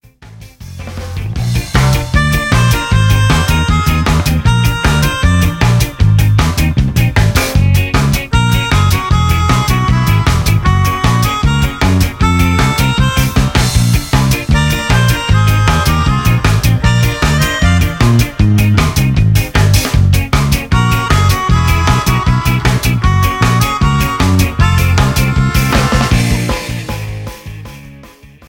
3 Melodica